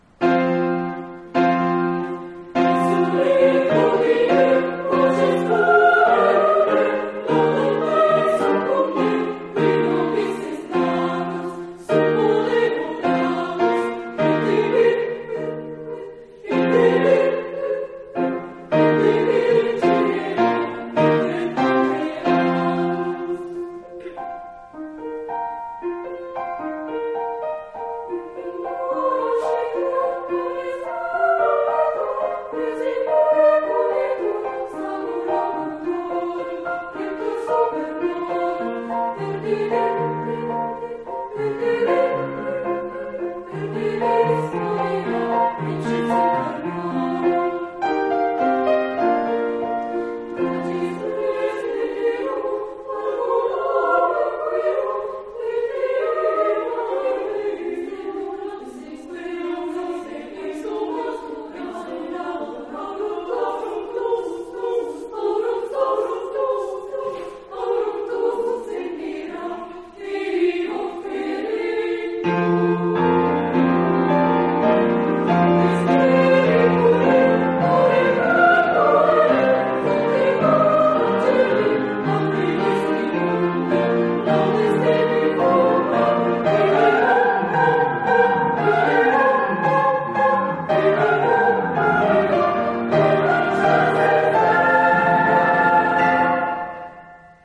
Parti per i soprani primi
Personent (sopr. I) QUALITA' BASSA DI REGISTRAZIONE